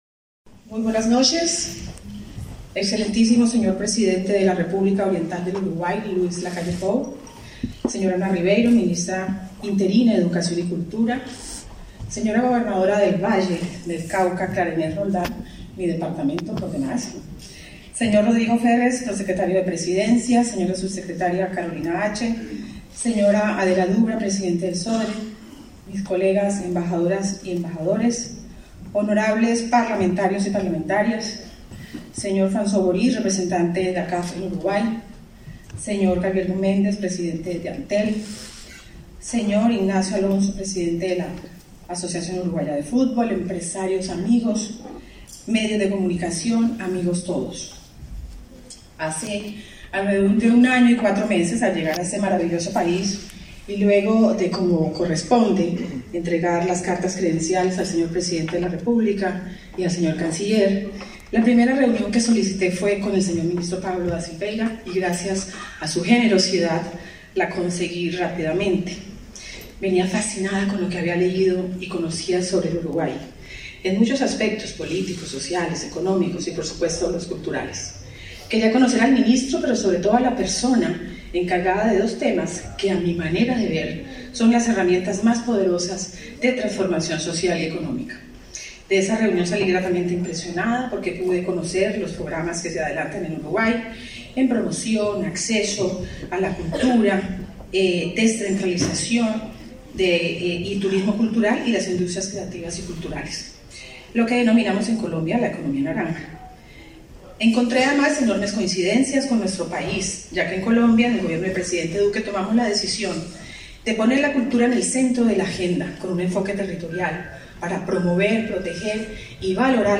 Conferencia de prensa por lanzamiento de Semana Internacional del Sodre
Con la asistencia del presidente de la República, Luis Lacalle Pou, se realizó el lanzamiento de la Semana Internacional del Sodre, este 19 de julio.
Participaron en el evento la ministra interina de Educación y Cultura, Ana Ribeiro; la presidenta del Consejo Directivo del Sodre, Adela Dubra, y la embajadora de Colombia en Uruguay, Carmen Vásquez.